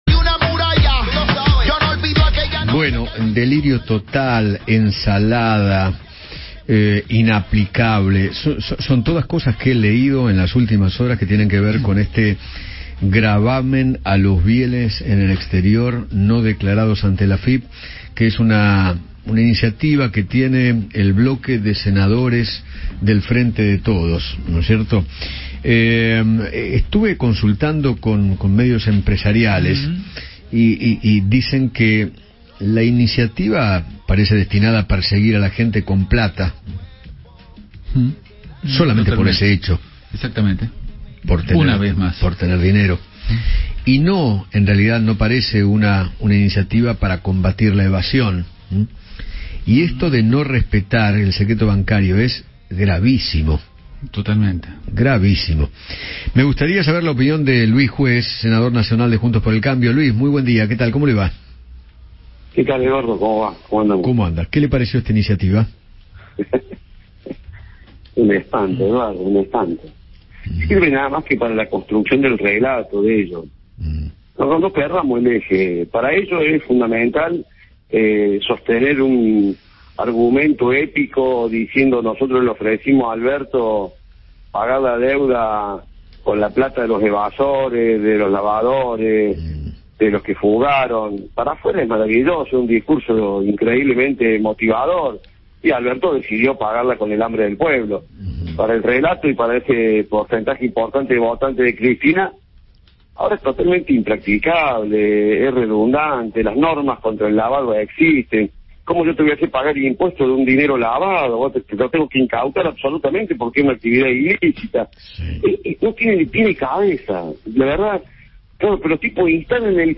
Luis Juez, senador de Juntos por el Cambio, habló con Eduardo Feinmann sobre el proyecto de ley que busca imponer el oficialismo para que quienes tengan bienes en el exterior sin declarar paguen un impuesto y expresó que “si la AFIP decide salir a perseguir a los evasores tiene todas las herramientas”.